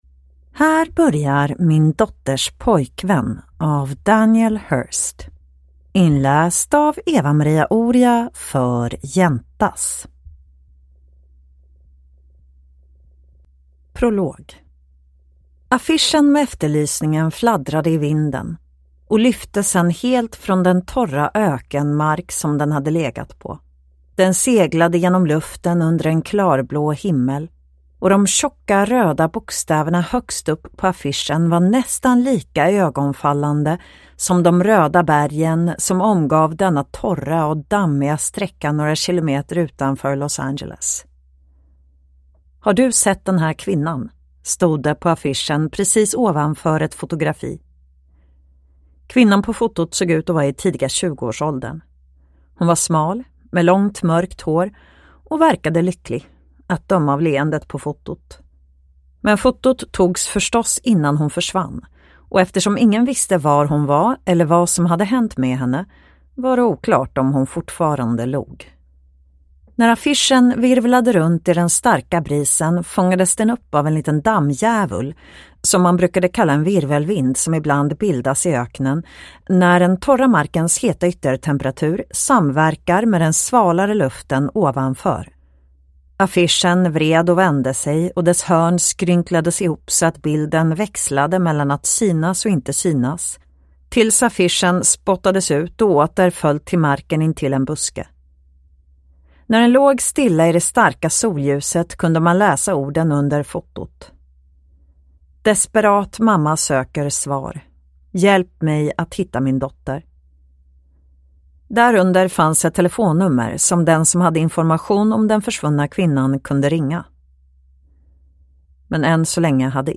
Min dotters pojkvän (ljudbok) av Daniel Hurst